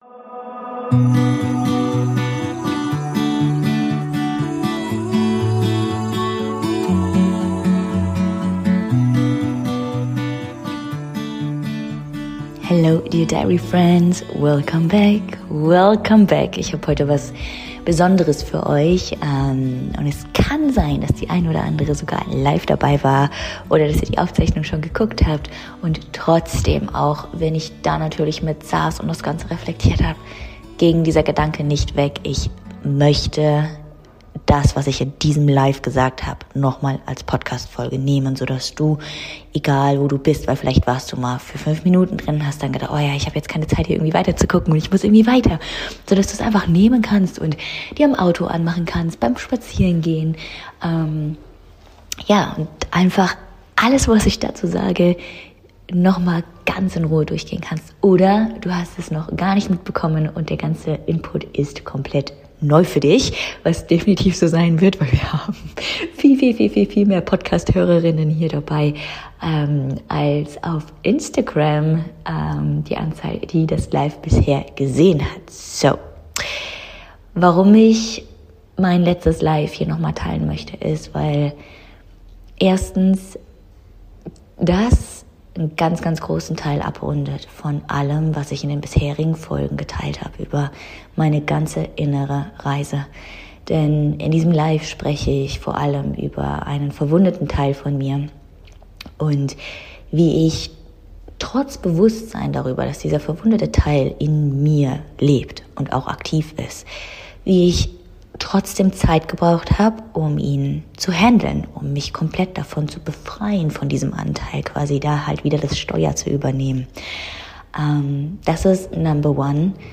Welcome back Dear Diary Friends🤍 Diese Folge ist eine ganz besondere Folge, denn ich teile mein letztes IG Live mit dir, in dem ich über meine innere Reise der letzten Monate spreche.